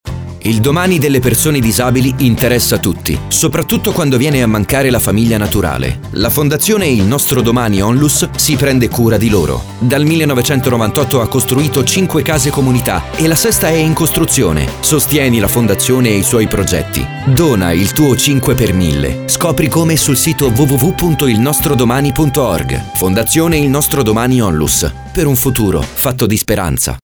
MESSAGGIO VOCALE 5 PER MILLE